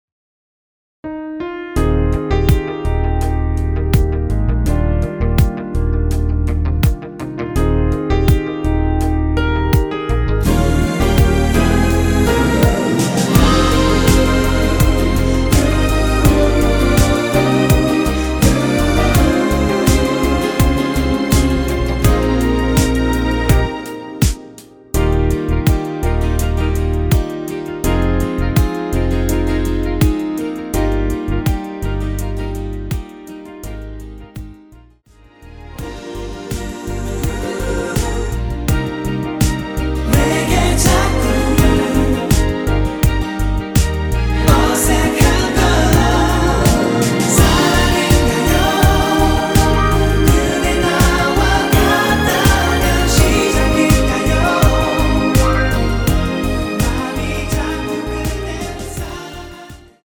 원키(1절+후렴) 코러스 포함된 MR입니다.
Eb
앞부분30초, 뒷부분30초씩 편집해서 올려 드리고 있습니다.